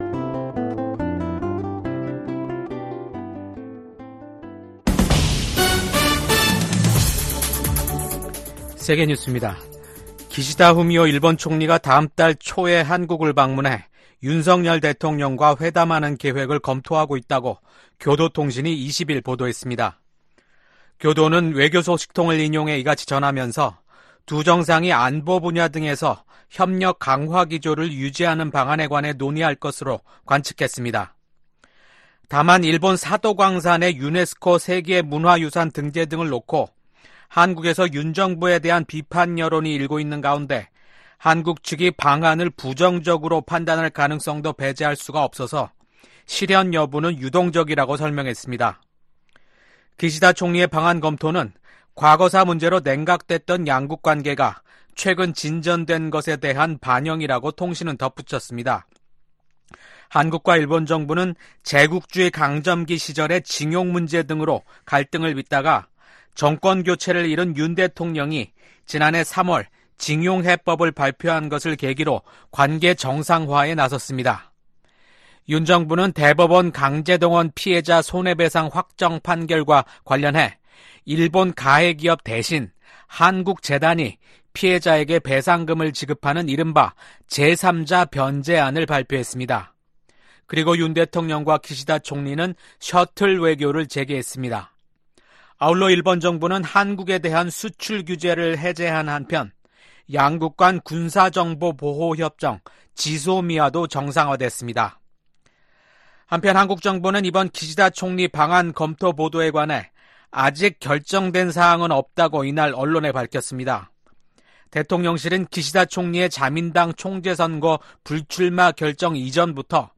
VOA 한국어 아침 뉴스 프로그램 '워싱턴 뉴스 광장' 2024년 8월 21일 방송입니다. 11월 대선을 앞두고 미국 민주당이 북한의 위협에 맞선 한국에 대해 변함없는 지지를 재확인하는 새 정강을 발표했습니다. 지난해 8월 캠프 데이비드에서 열린 미한일 3국 정상회의는 동북아에서 3국의 안보 협력을 한 단계 끌어올린 대표적 외교 성과라고 미 전문가들이 평가했습니다. 북한 군 병사 한 명이 20일 새벽 동부전선 군사분계선을 넘어 한국으로 망명했습니다.